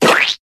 SuperGlueApplied.ogg